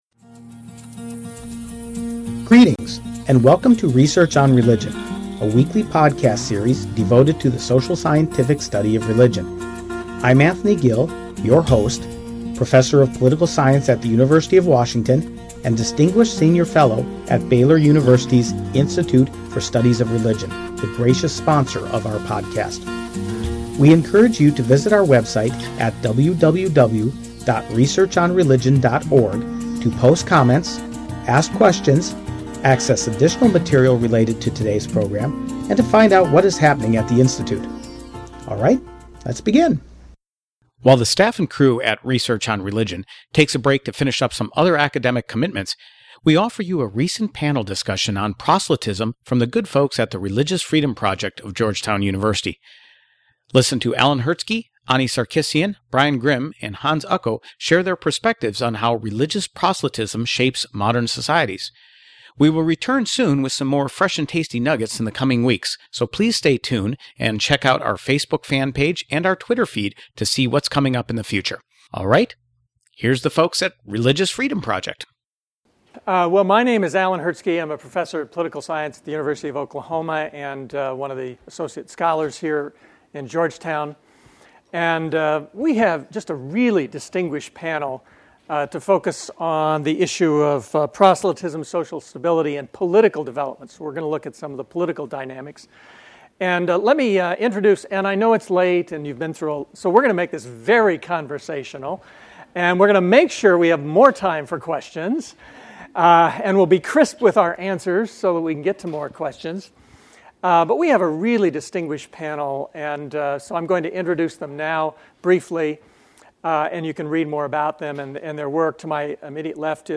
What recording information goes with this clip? While the staff and crew at Research on Religion take a short break to complete other academic commitments, we offer you a panel discussion recently held by the Religious Freedom Project of Georgetown University’s Berkeley Center for Religion, Peace, and World Affairs.